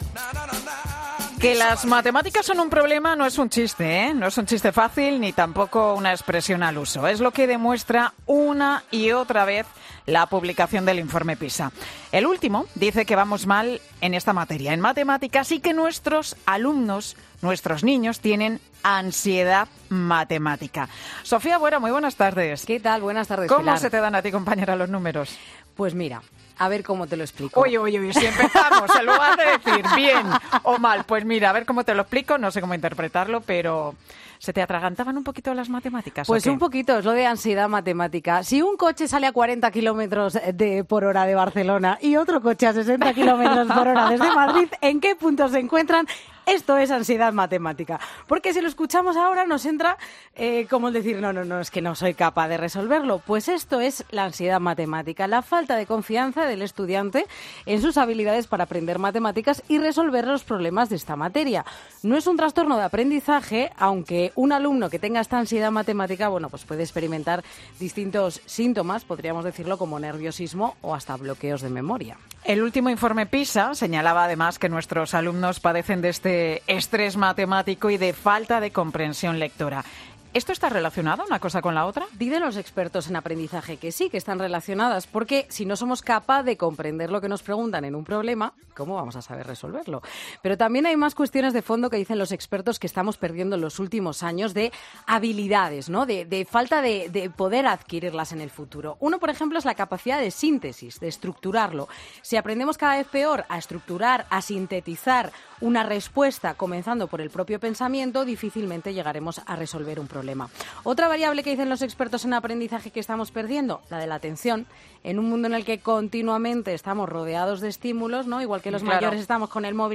Ahora que el Gobierno anuncia un plan para reforzar la comprensión en Matemáticas, este profesor nos propone en Mediodía COPE las claves para dejar de...